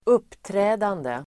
Uttal: [²'up:trä:dande]